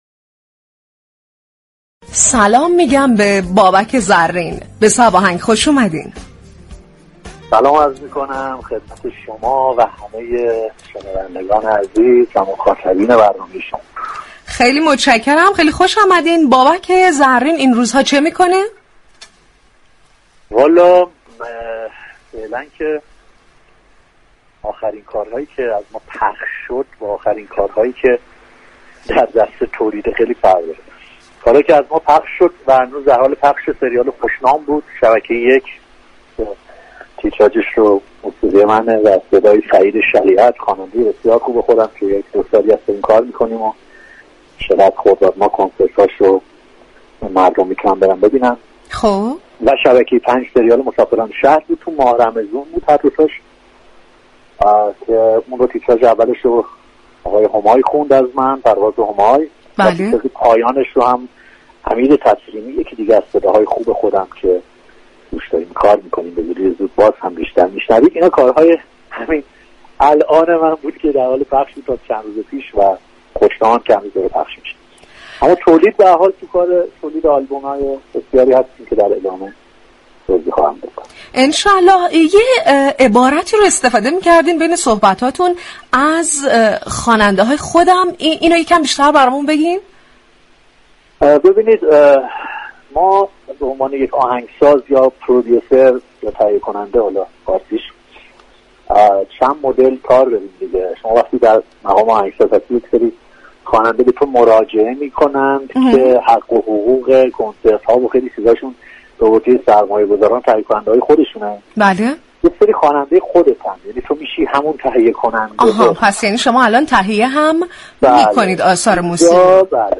بابك زرین در گفتگو با رادیو صبا درباره اختلاف نظرش با سالار عقیلی توضیح داد.